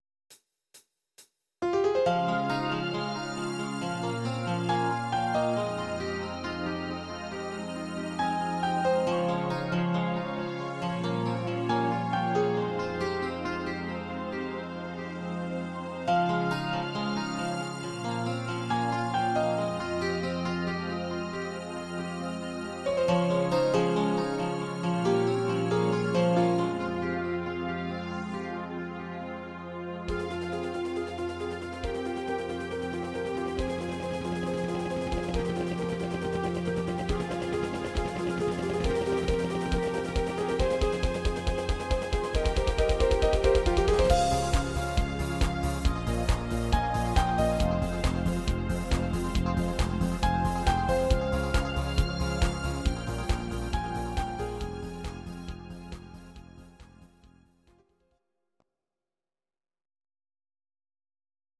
instr. Synthesizer